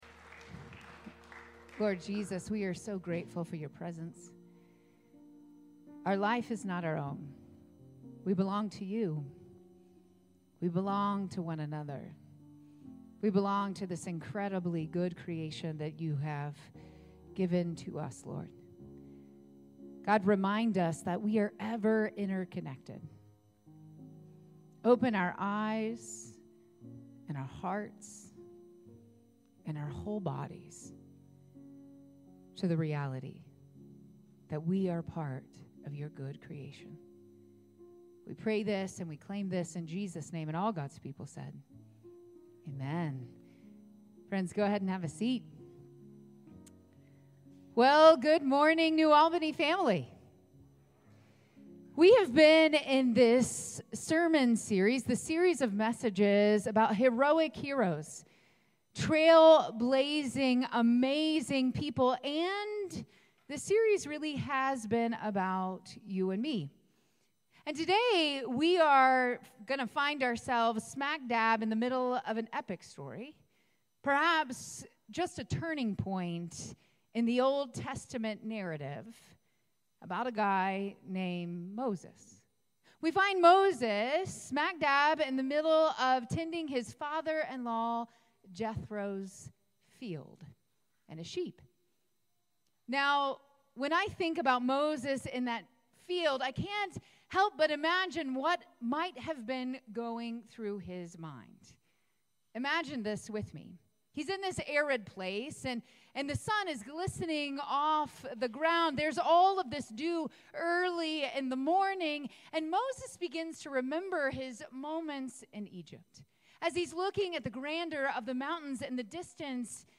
9:30 Redemption Worship Service May 18, 2025